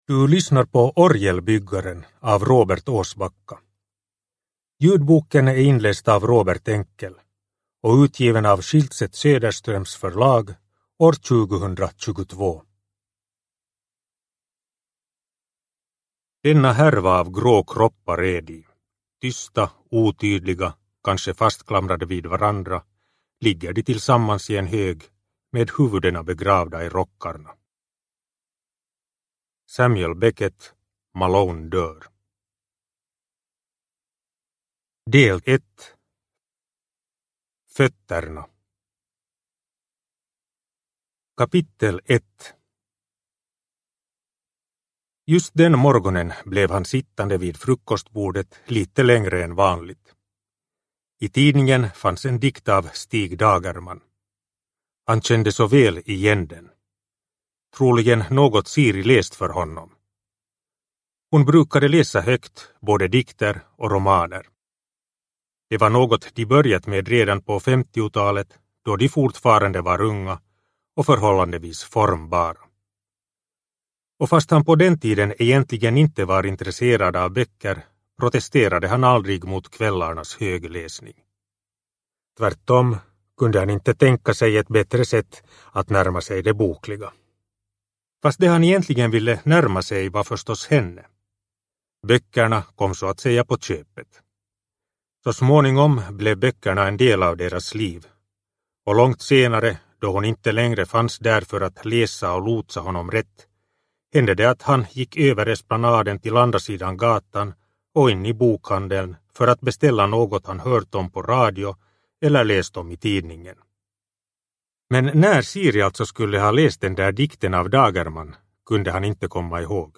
Orgelbyggaren – Ljudbok – Laddas ner